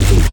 Key-bass_8.3.3.wav